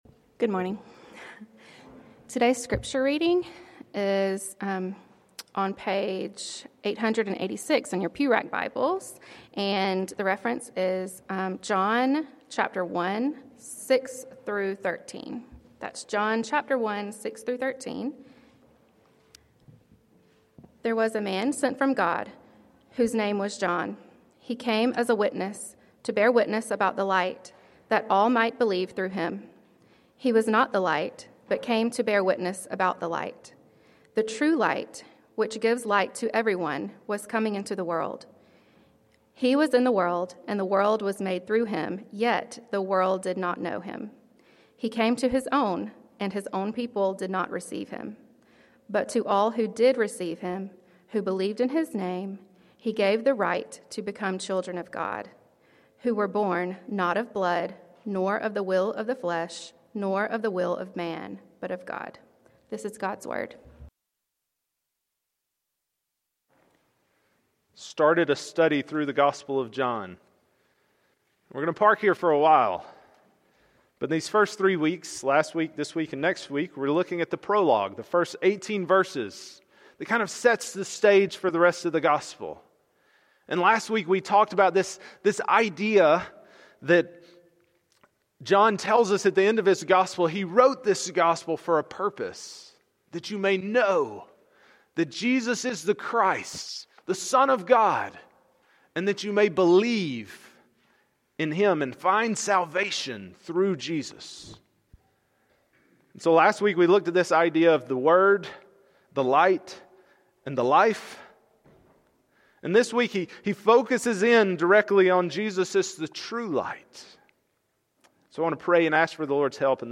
Passage: John 1:6-13 Sermon